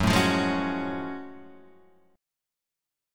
F#mM9 chord {2 4 3 2 2 4} chord